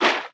Sfx_creature_babypenguin_swim_fast_06.ogg